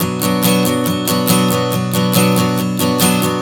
Strum 140 Bm 02.wav